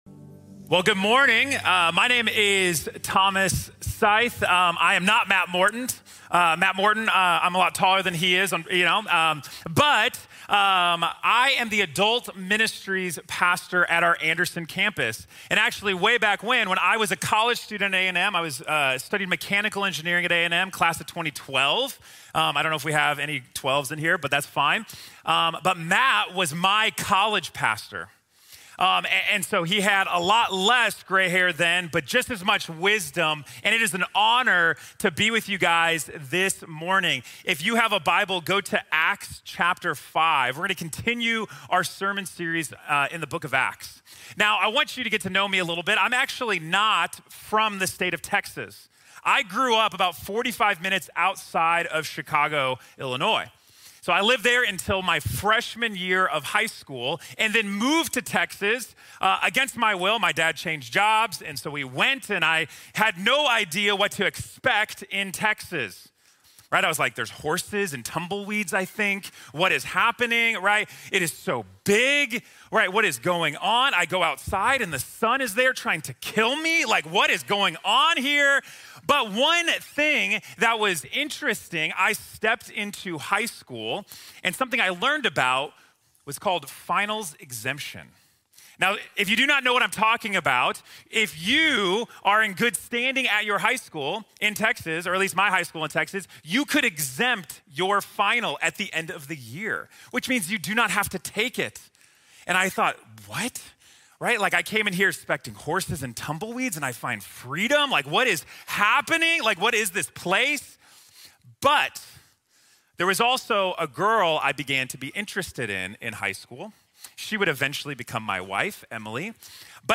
La tragedia de Ananías y Safira | Sermón | Iglesia Bíblica de la Gracia